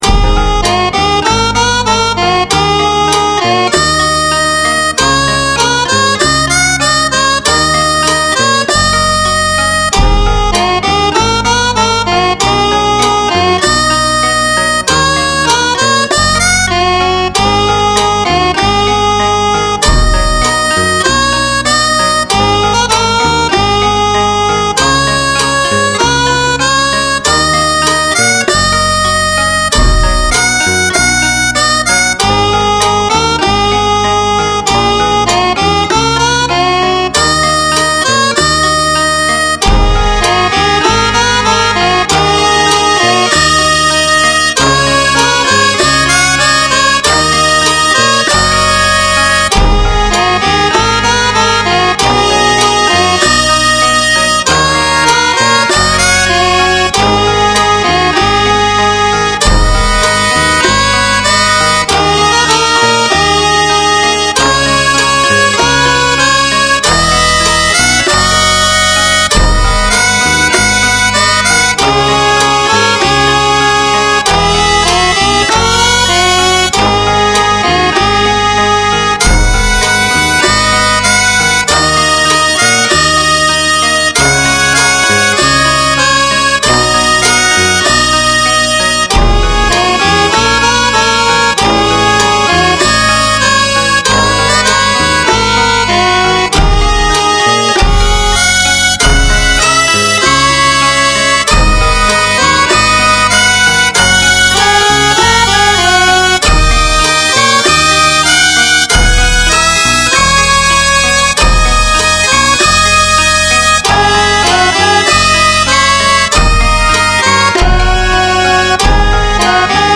*レン シリーズ（和風な編成）